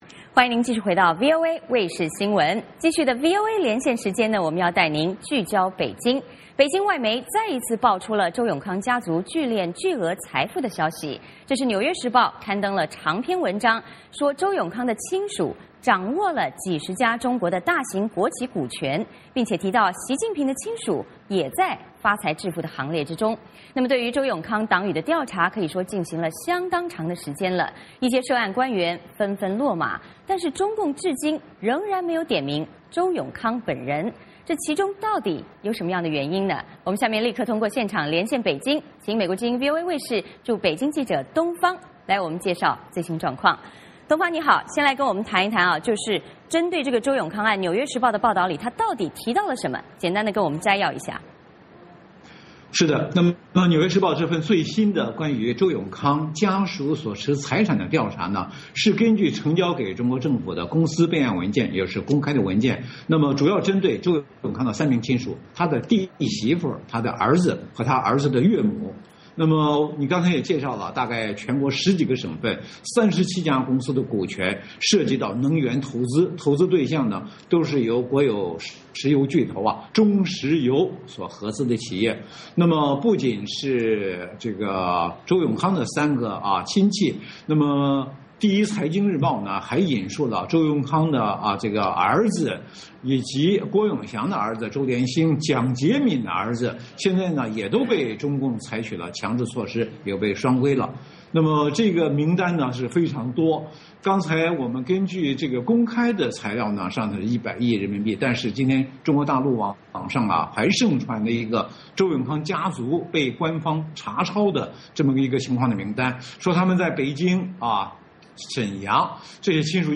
我们就现场连线北京